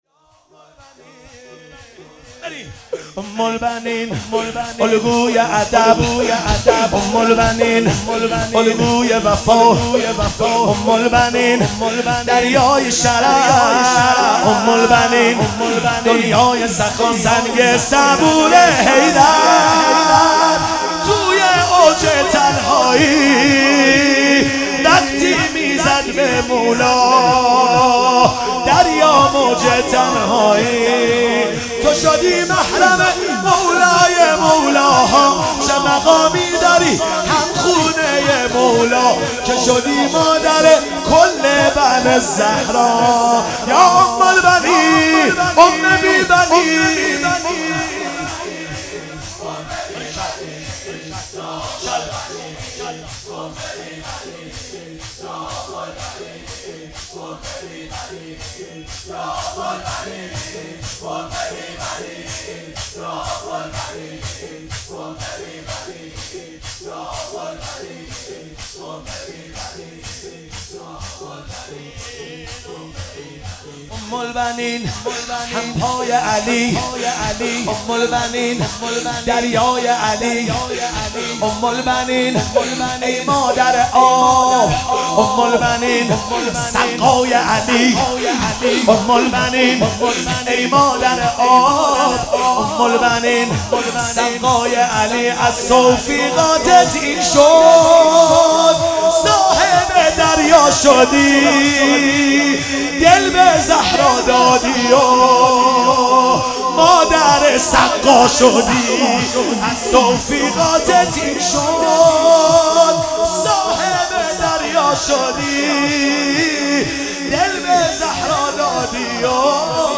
شهادت حضرت ام البنین سلام الله علیها96 - شور - ام البنین الگوی ادب
شهادت حضرت ام البنین سلام الله علیها